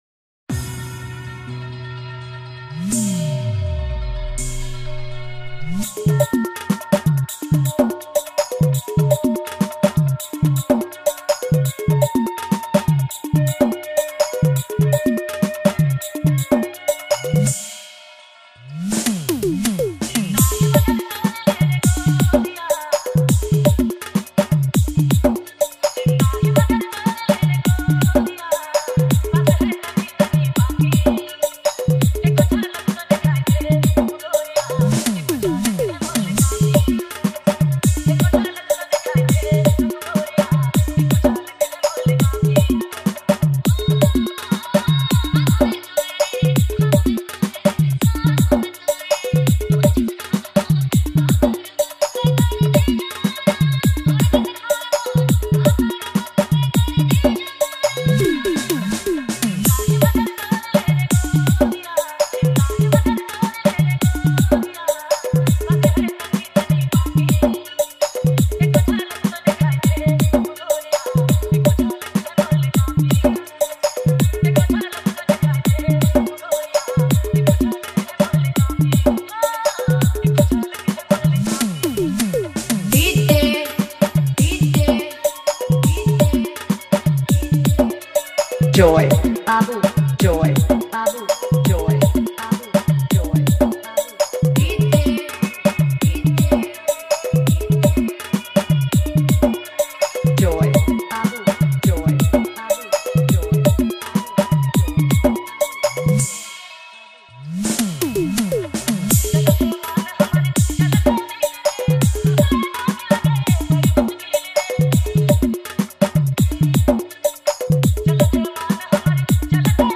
Dj Remixer